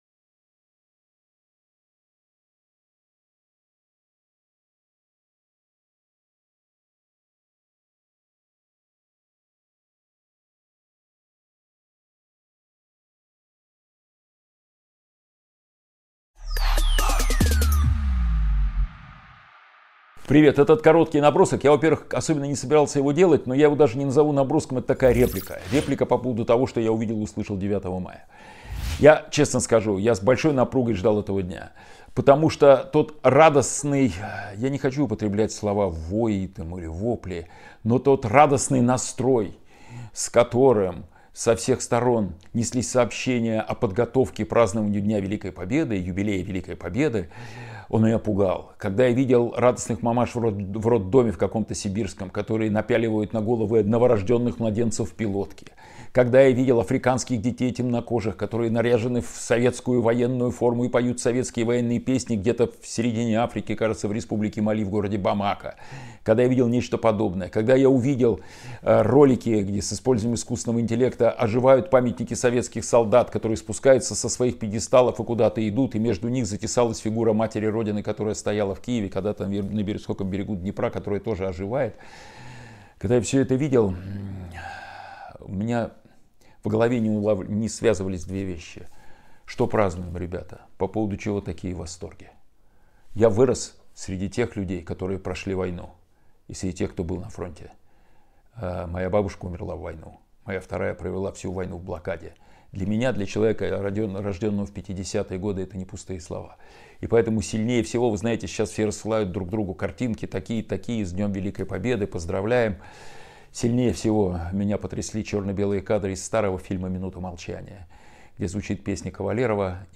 Эфир ведёт Кирилл Набутов